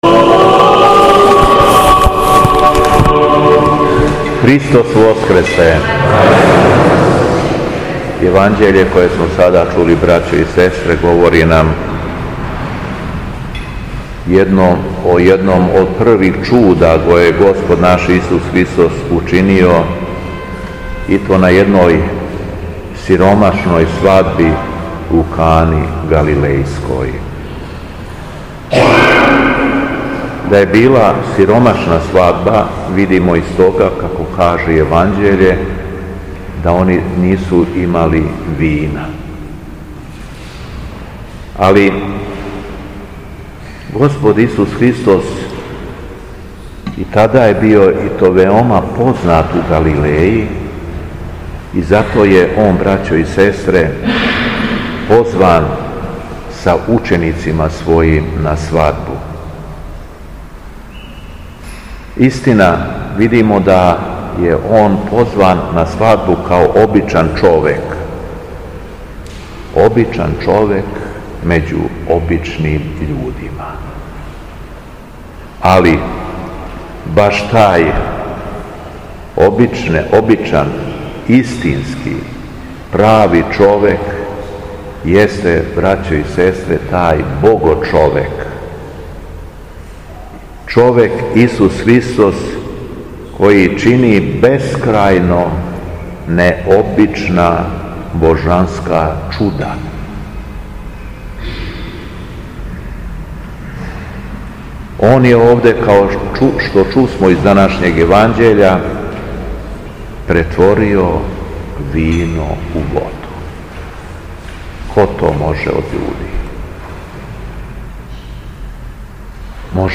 СВЕТА АРХИЈЕРЕЈСКА ЛИТУРГИЈА У ХРАМУ СВЕТОГА САВЕ У КРАГУЈЕВЦУ - Епархија Шумадијска
Беседа Његовог Високопреосвештенства Митрополита шумадијског г. Јована